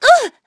Xerah-Vox_Damage_01.wav